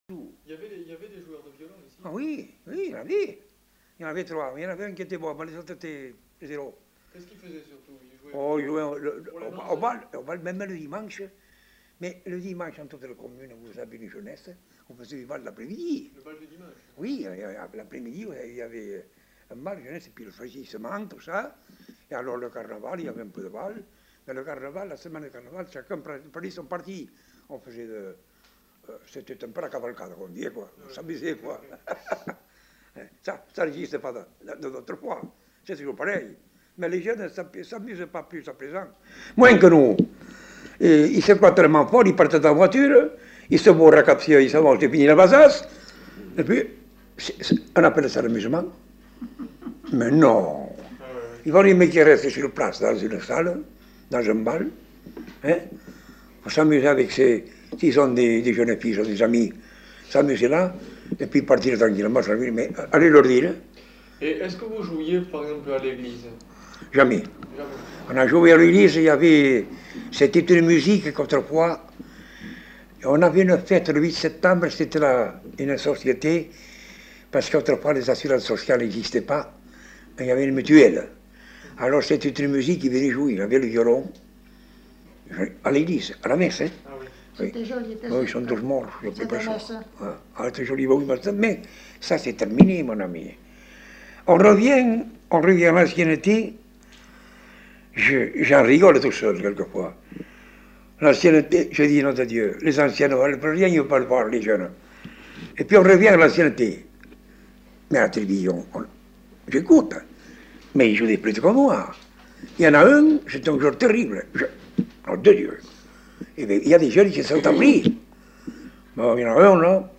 Genre : témoignage thématique